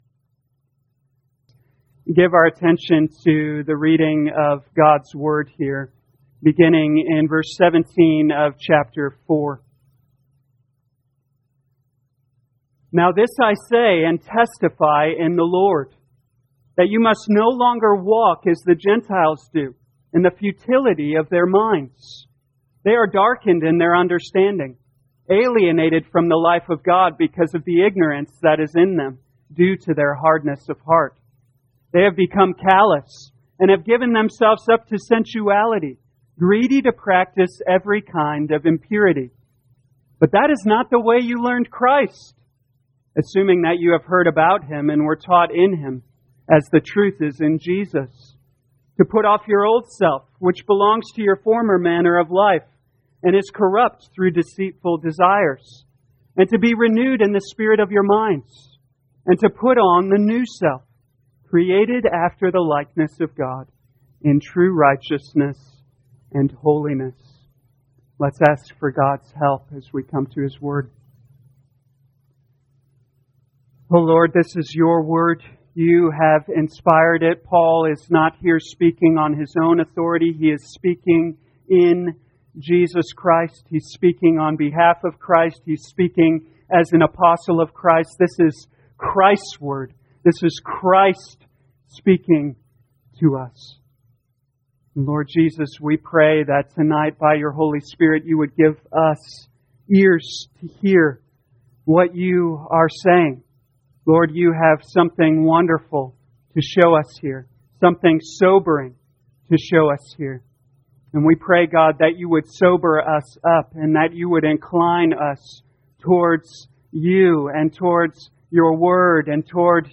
2021 Ephesians Evening Service Download
You are free to download this sermon for personal use or share this page to Social Media. Becoming What You Are In Christ Scripture: Ephesians 4:17-24